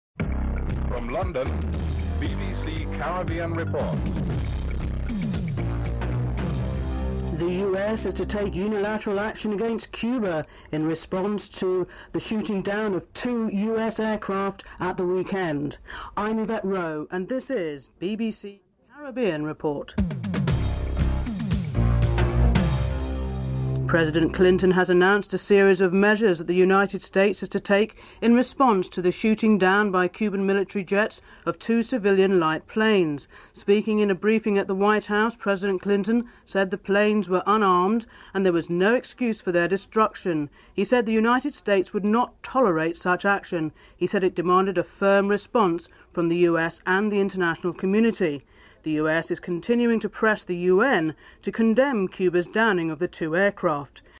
1. Headlines (00:00:00:22)